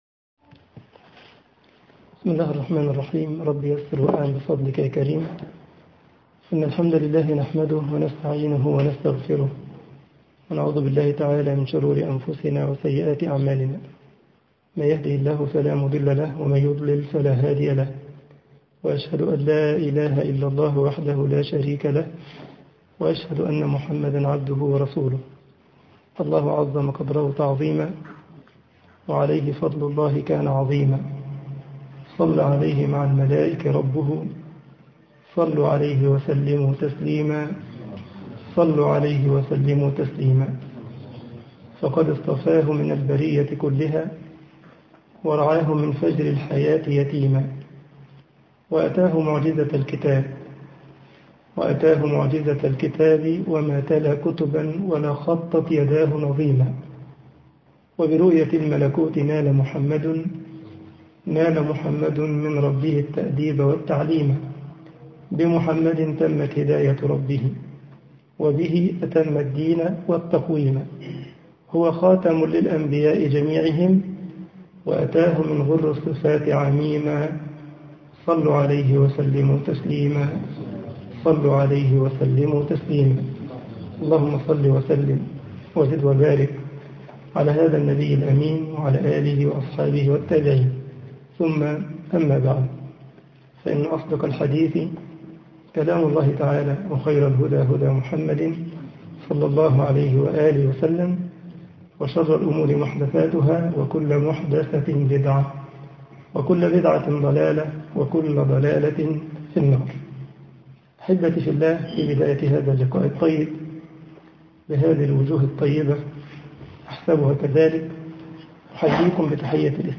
محاضرة
مسجد السلام بمدينة اسن - ألمانيا